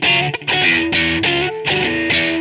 GUITAR LOOPS - PAGE 1 2 3 4